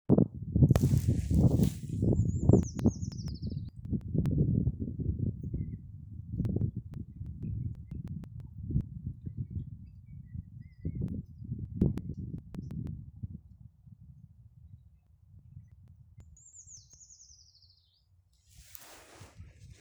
Birds -> Treecreepers ->
Common Treecreeper, Certhia familiaris
StatusSinging male in breeding season